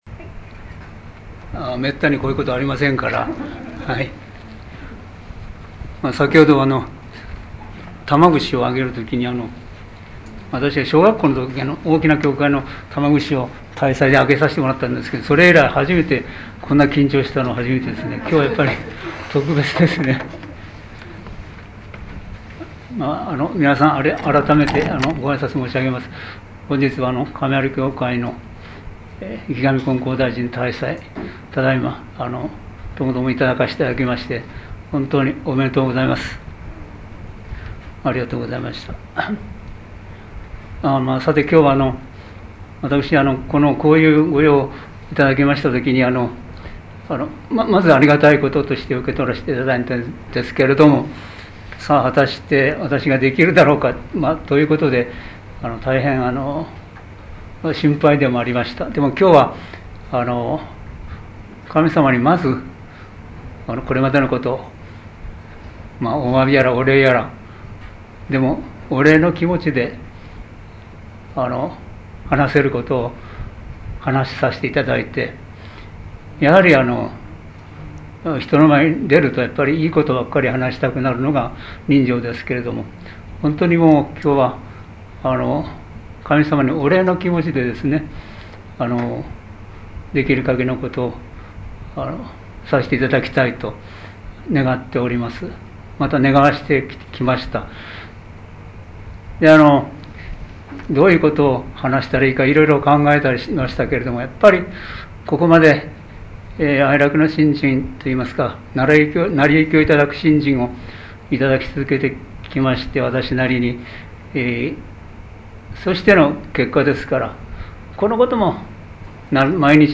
生神金光大神大祭･感話発表